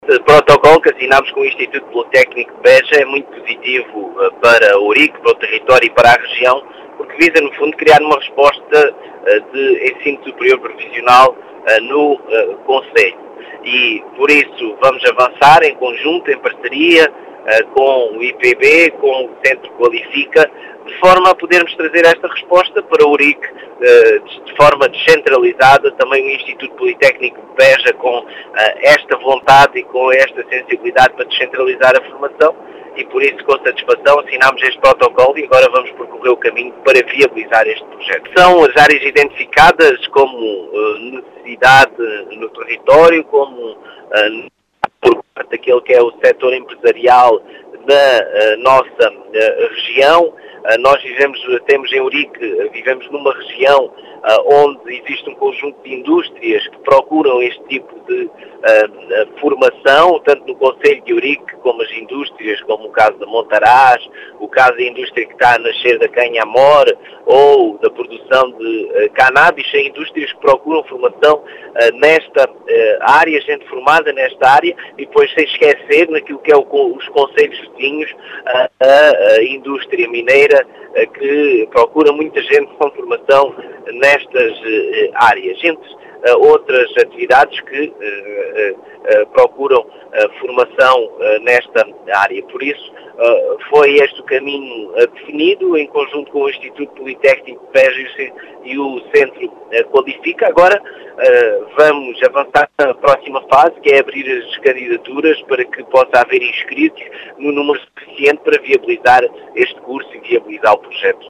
As explicações são do presidente da Câmara de Ourique, Marcelo Guerreiro, que realçou a importância desta formação no concelho.